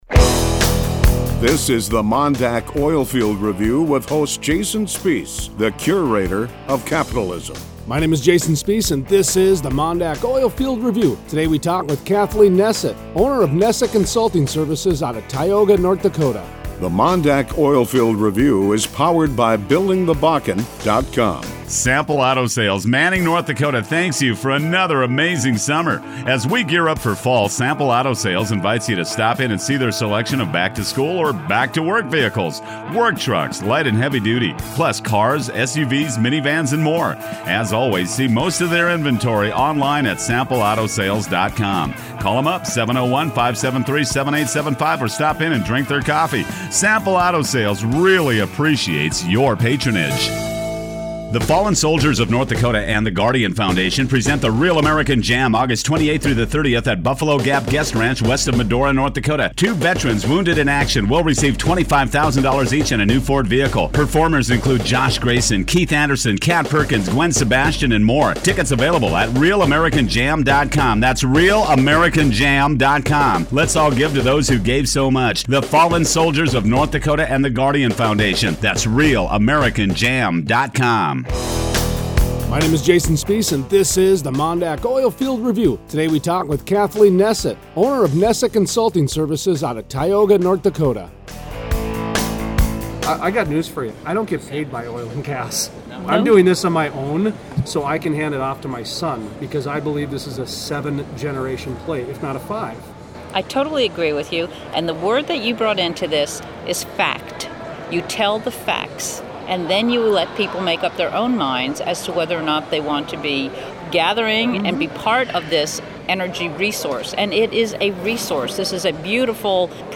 Thursday 8/20 Interview